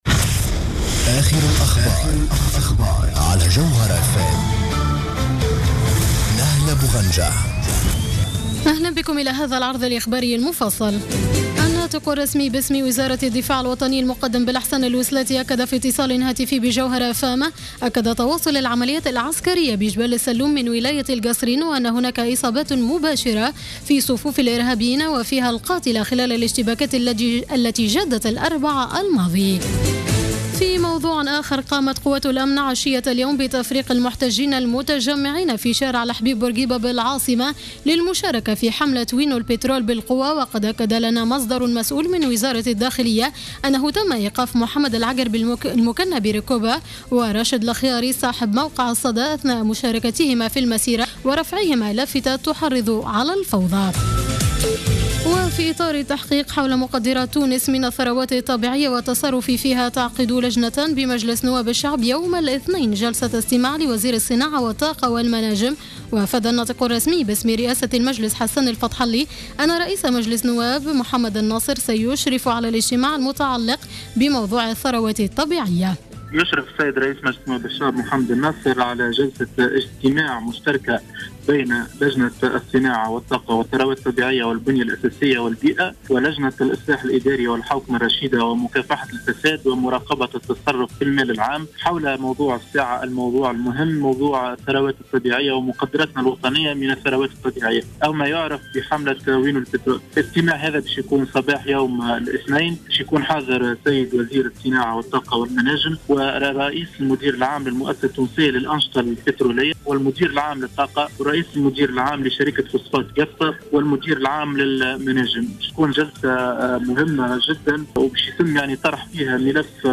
نشرة أخبار السابعة مساء ليوم السبت 06 جوان 2015